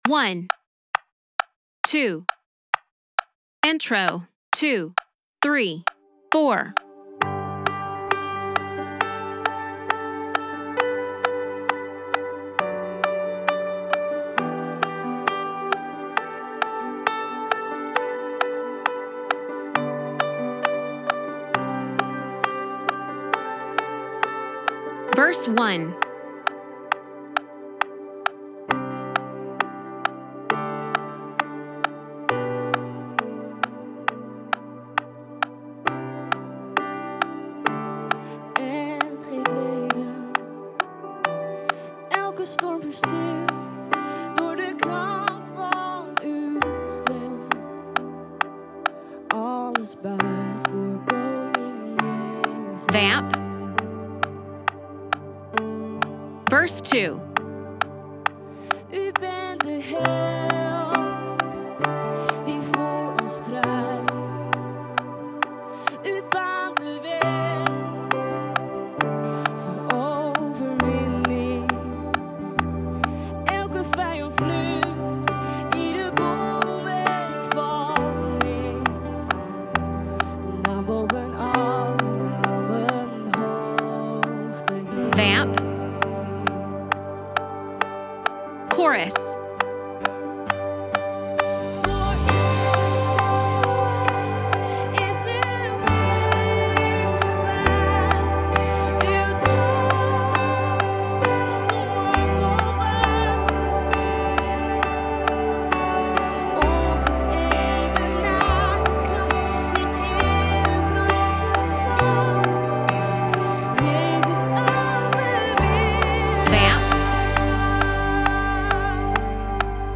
Jezus Overwinnaar Db - tracks and guide and click.mp3